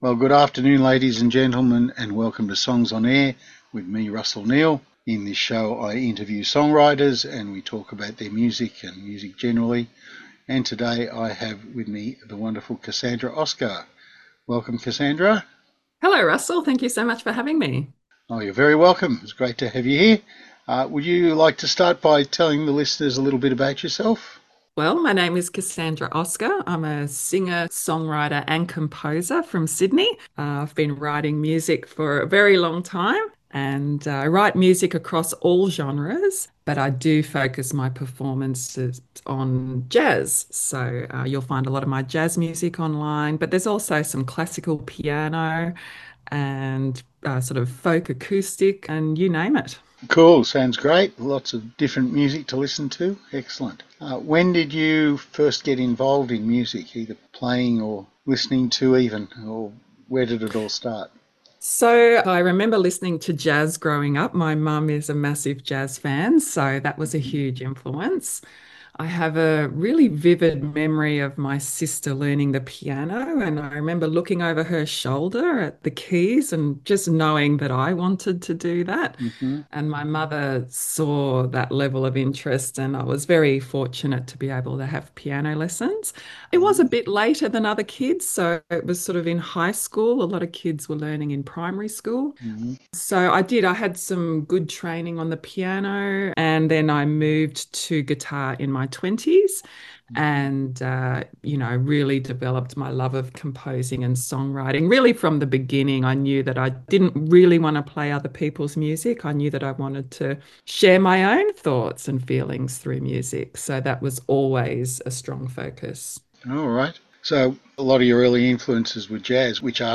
Northern Beaches radio interview